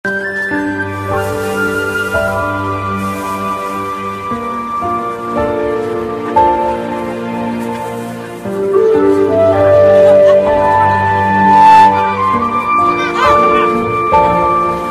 SFX音效